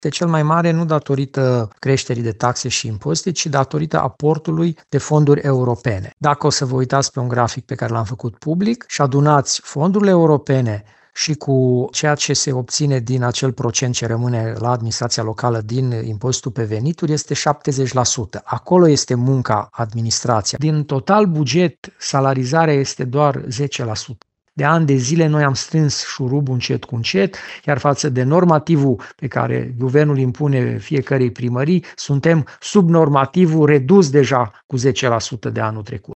Primarul Aradului, Călin Bibarț, spune că principalele investiții sunt direcționate spre școli, energie, transport și sport.